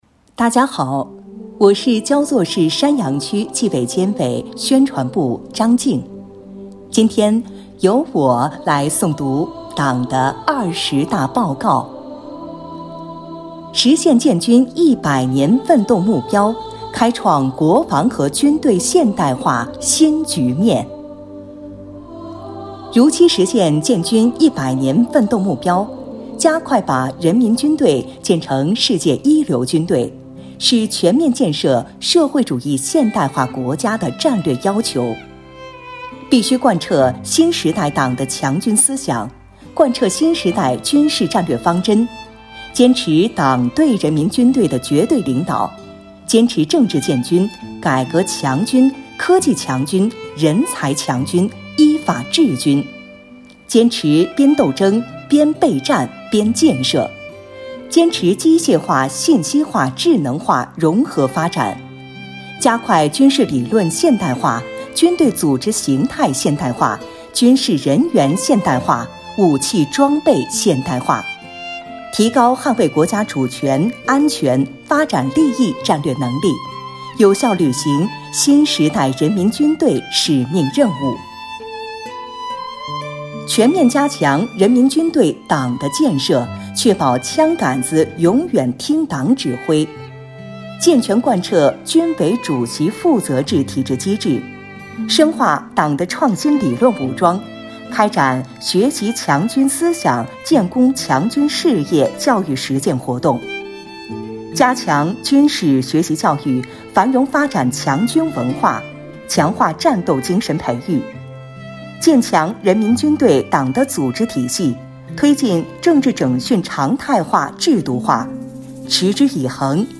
为深入学习宣传贯彻党的二十大精神，切实把全市纪检监察干部的思想统一到党的二十大精神上来，把力量凝聚到党的二十大确定的各项任务上来，即日起，“清风焦作”公众号推出“共学二十大•接力读原文”活动，摘选各单位宣讲员诵读党的二十大报告原文，敬请关注！